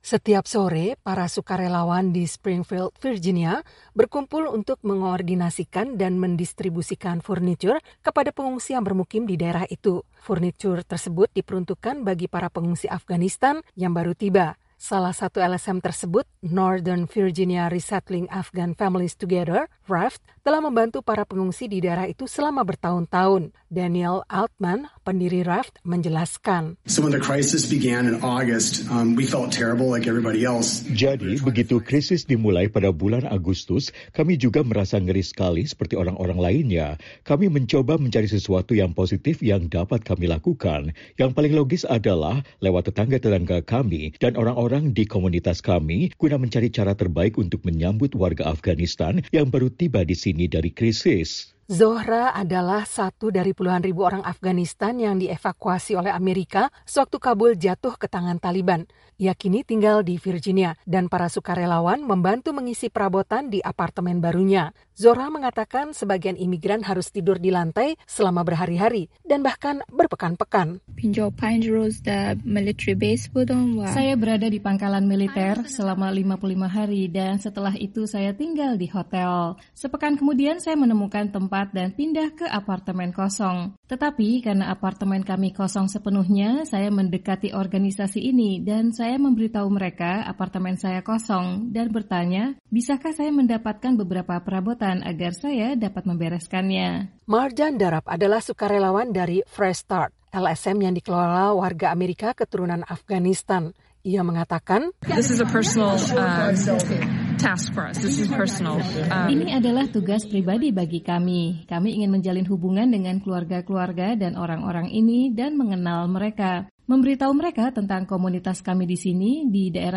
Dua LSM lokal membantu keluarga-keluarga Afghanistan yang baru tiba untuk bermukim di Virginia. Berikut ini laporan tim VOA.